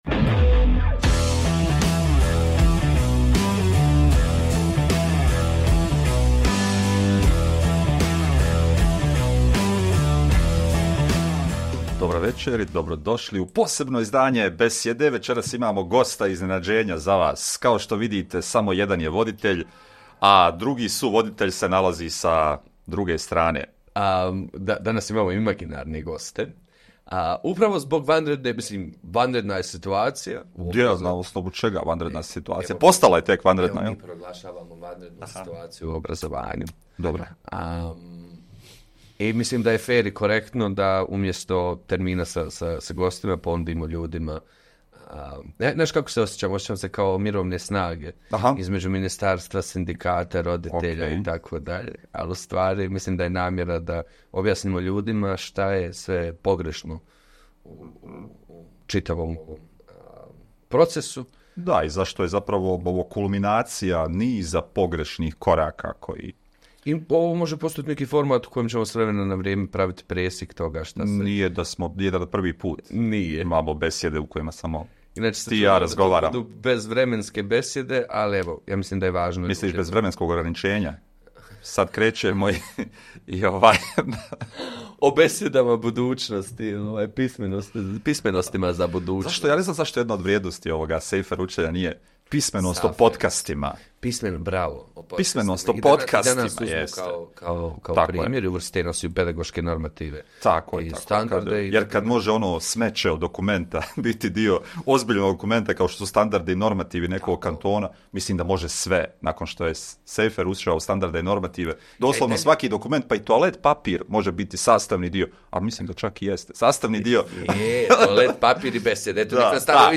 U ovoj epizodi Besjeda nema gosta, voditelji govore o aktuelnim obrazovnim temama – militarizaciji djetinjstva, javnim verbalnim sukobima, nasilja u kojem djeca odrastaju u BiH, kako se prema tome…